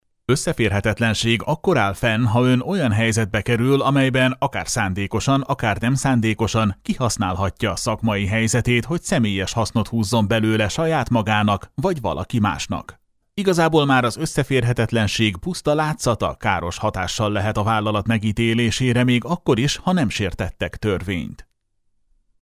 Male
30s, 40s, 50s
Character, Confident, Energetic
Animation, Commercial, E-Learning, Video Game
Microphone: Shure SM7B
Audio equipment: Focusrite Scarlett 2i2 4th Gen